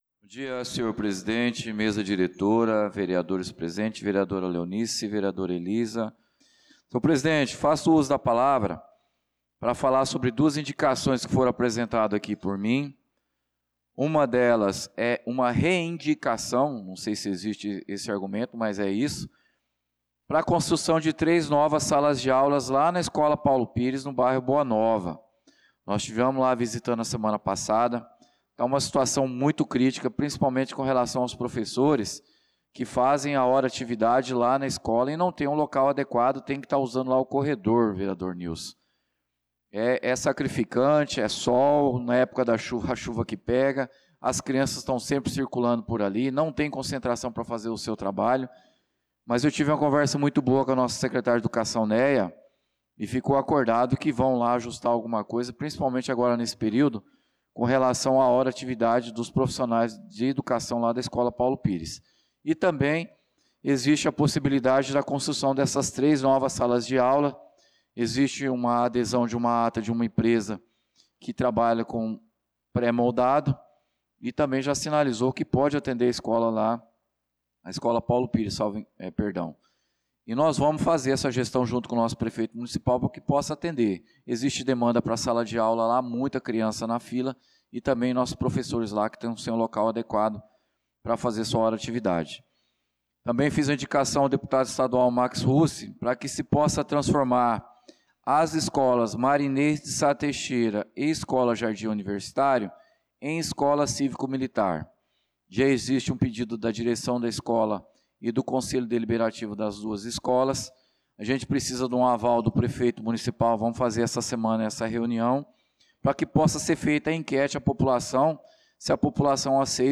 Pronunciamento do vereador Claudinei de Jesus na Sessão Ordinária do dia 09/06/2025